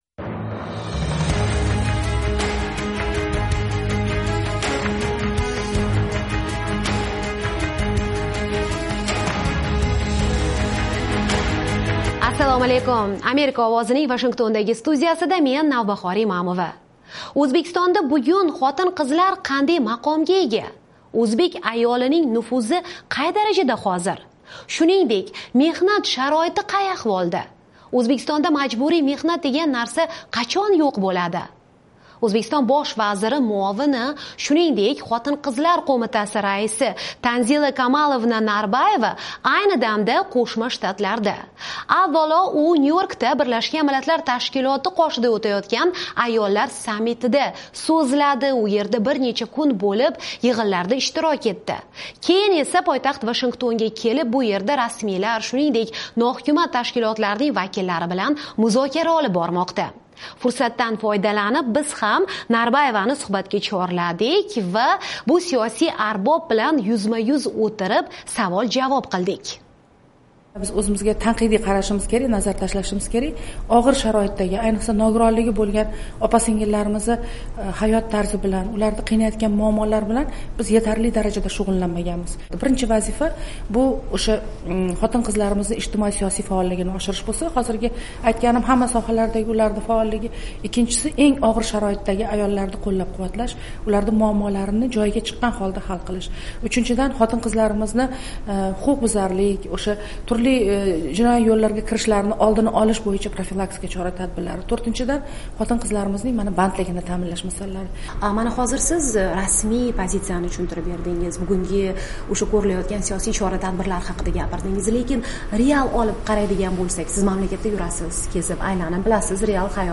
O'zbekiston Bosh vaziri o'rinbosari bilan muloqotdan bir shingil
O'zbekistonda bugun xotin-qizlar qanday maqomga ega? Shuningdek, majburiy mehnat degan narsa qachon yo'q bo'ladi? Islohotlar jarayonida yetakchi rol o'ynayotgan Tanzila Narbaeva Bosh vazir muovini va O'zbekiston Ayollar Qo'mitasi raisi.